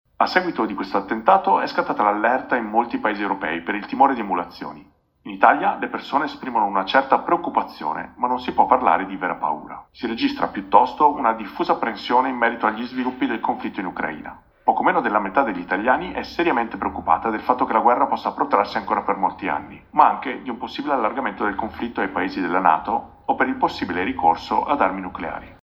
Società Archivi - Pagina 26 di 343 - Giornale Radio Sociale